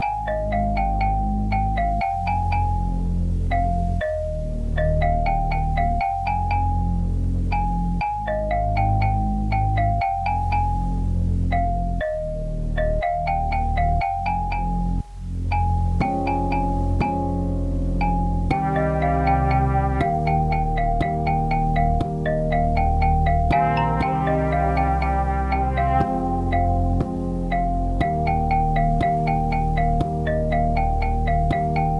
Tag: 120 bpm Trap Loops Bells Loops 5.38 MB wav Key : Unknown